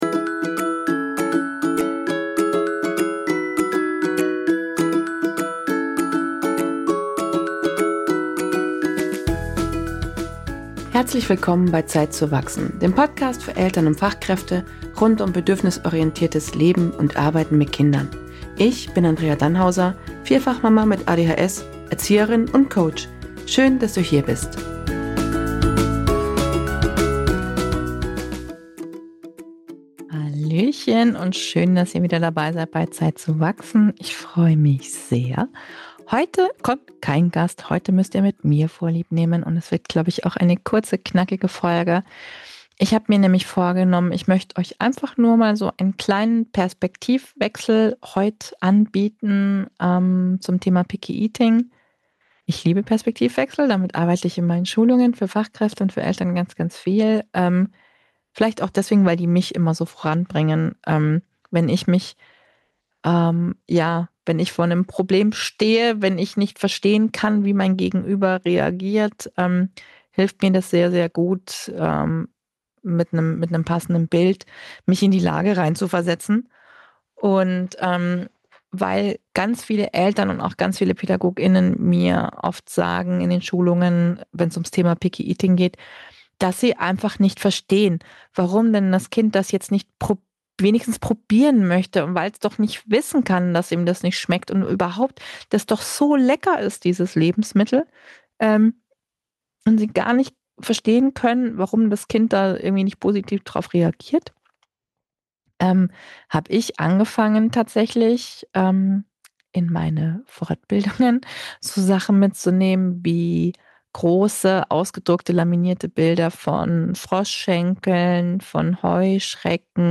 Solofolge mit Tipps zum Thema Picky Eating für Eltern und Fachkräfte